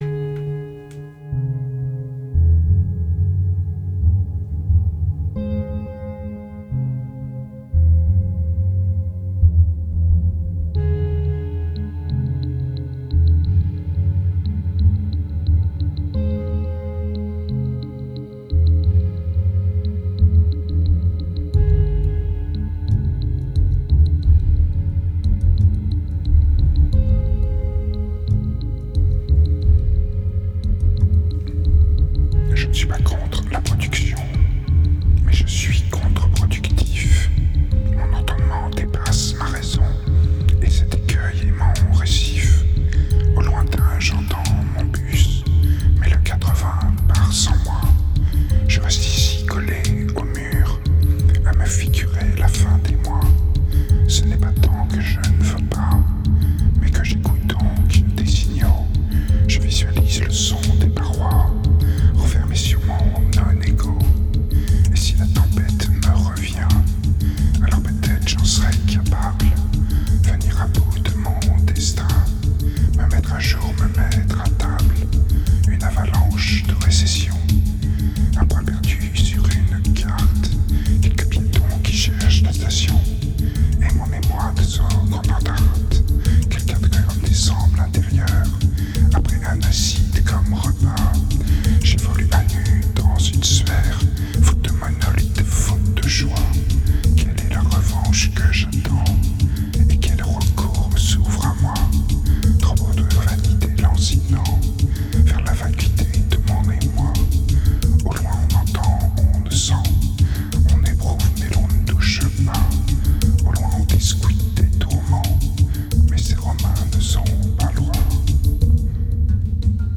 2308📈 - -8%🤔 - 89BPM🔊 - 2010-01-08📅 - -193🌟